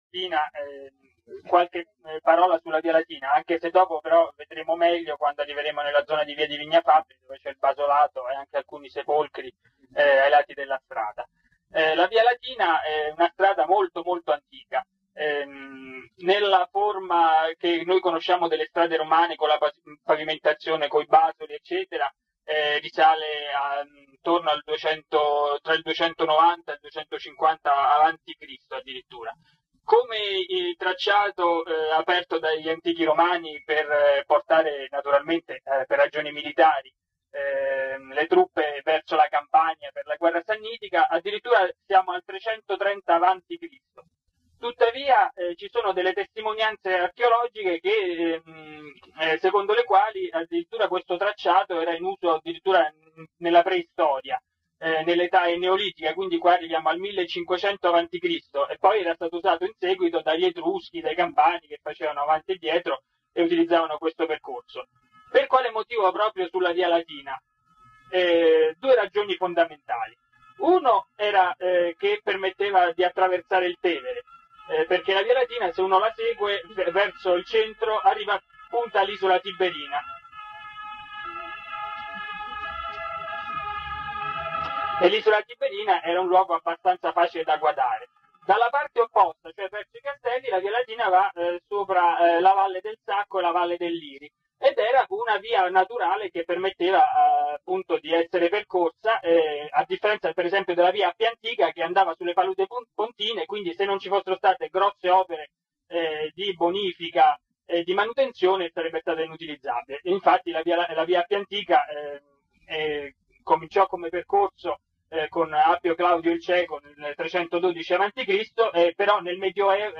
Audioguida storica (anni '90)